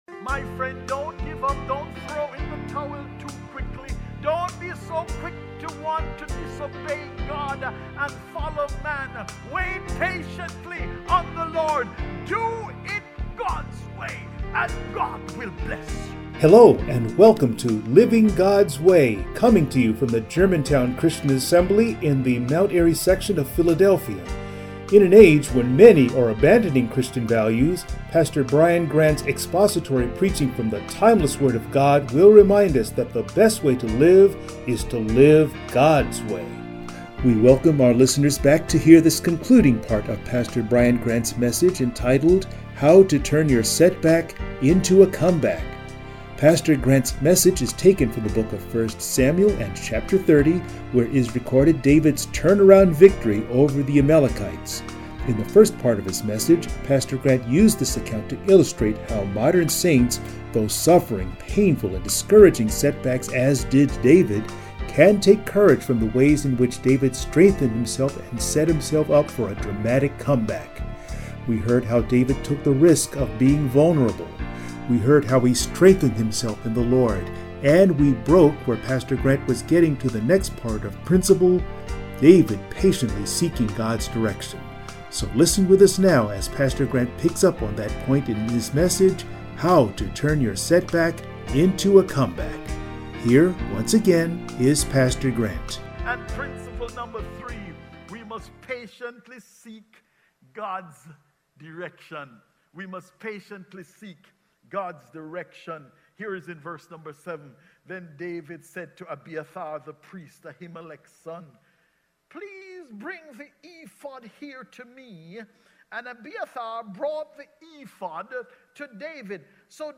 Passage: 1st Samuel, Chapter 30 Service Type: Sunday Morning